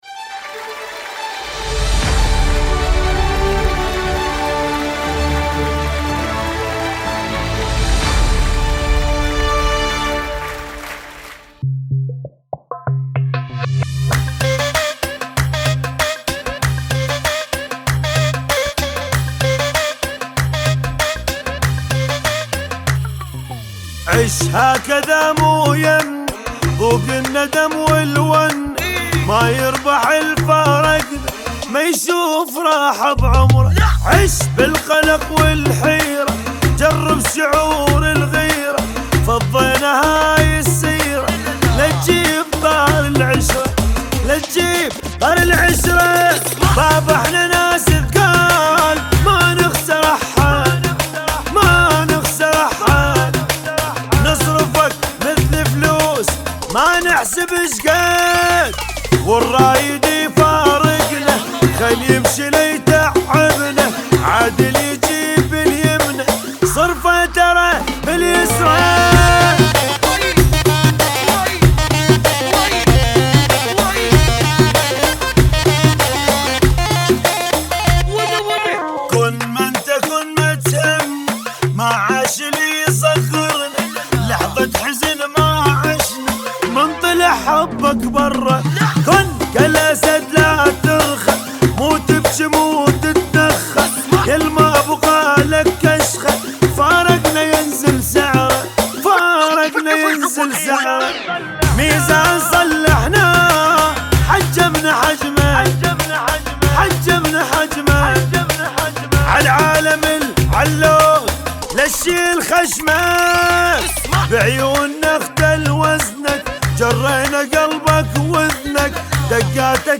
اغاني خليجيه